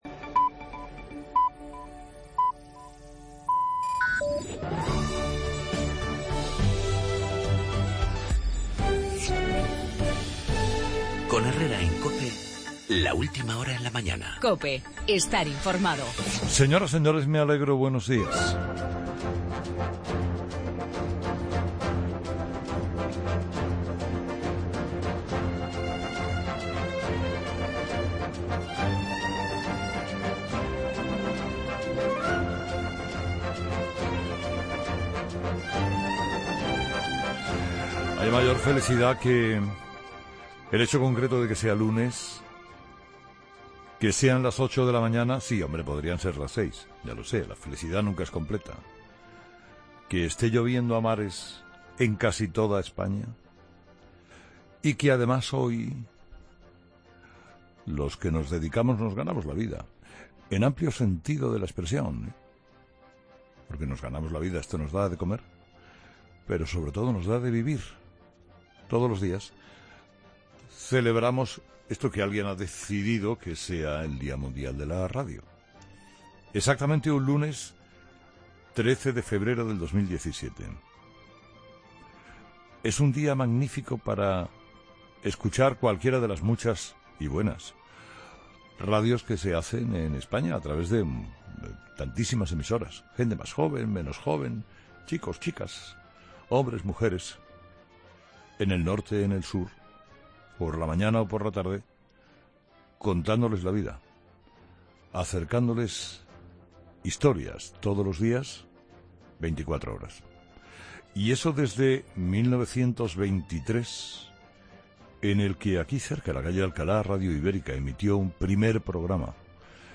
AUDIO: El Día Mundial de la Radio en el monólogo de Carlos Herrera a las 8 de la mañana.